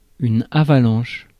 Ääntäminen
Ääntäminen France: IPA: [a.va.lɑ̃ʃ] Haettu sana löytyi näillä lähdekielillä: ranska Käännös Ääninäyte Substantiivit 1. avalanche US 2. deluge US Suku: f .